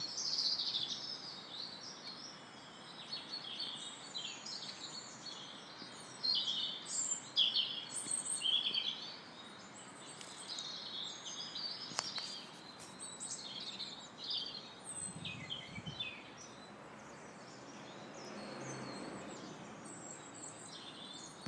Quality bird song this morn
Not sure of the bird flavour?